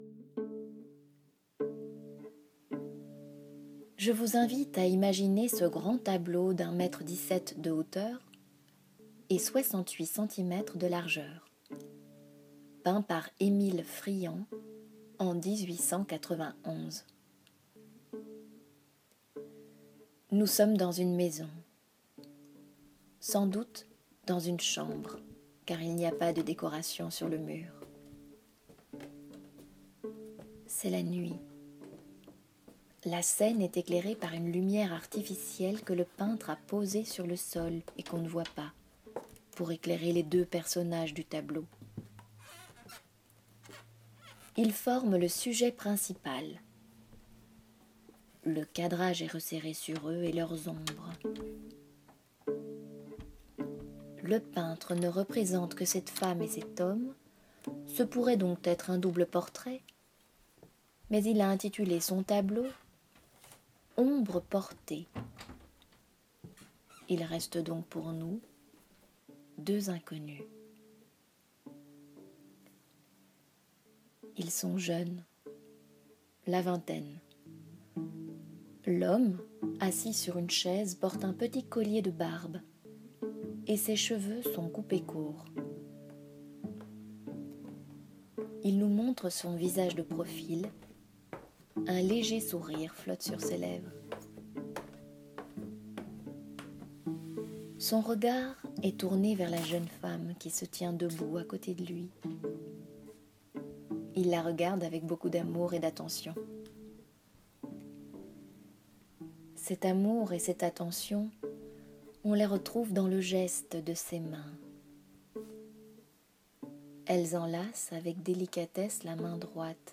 Une lecture audiodescriptive